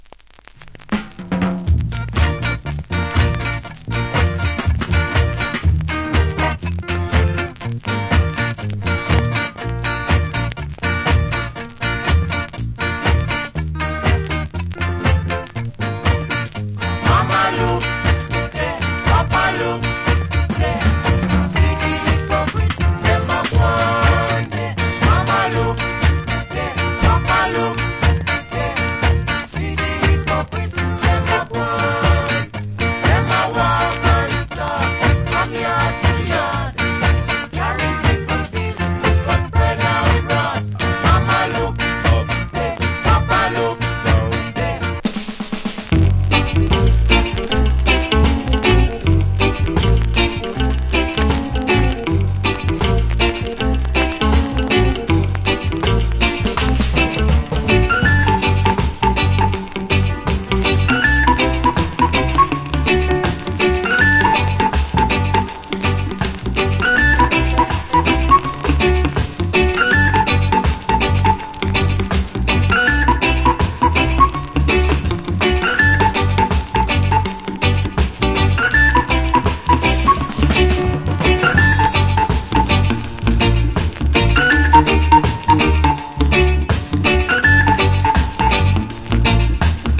Early Reggae Vocal. / Wicked Early Reggae Inst.